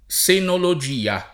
[ S enolo J& a ]